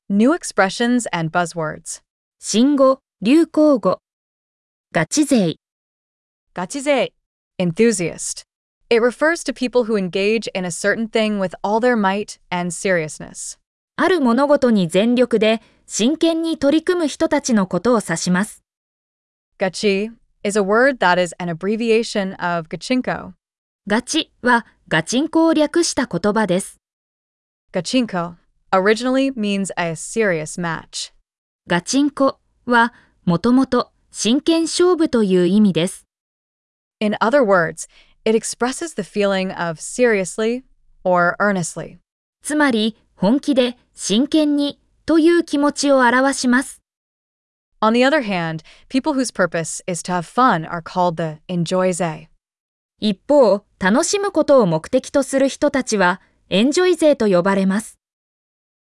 🗣 pronounced: Gachizei